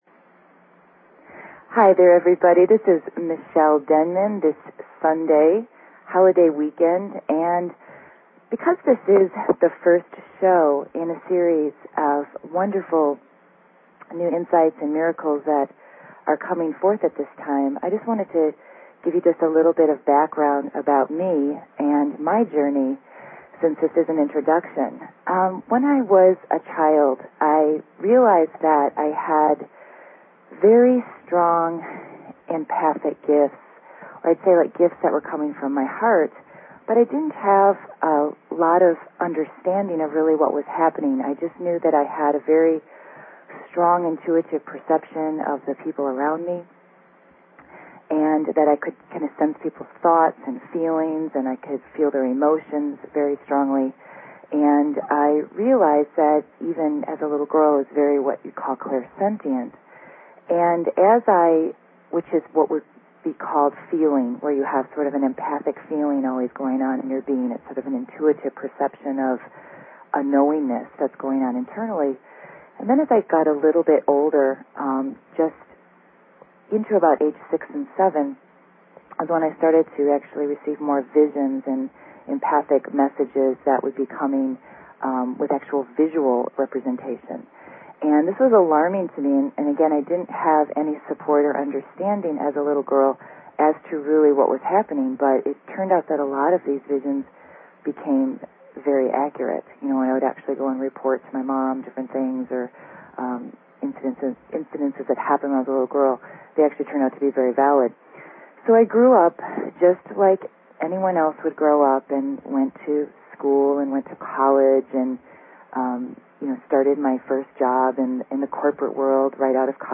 Talk Show Episode, Audio Podcast, Reaching_into_the_Stars and Courtesy of BBS Radio on , show guests , about , categorized as